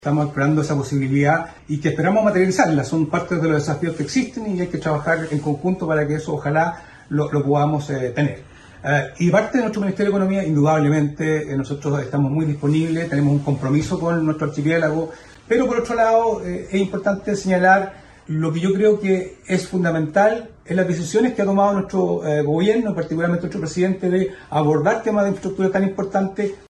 Asimismo, el seremi de Economía, Luis Cárdenas, indicó que el objetivo es trabajar en conjunto.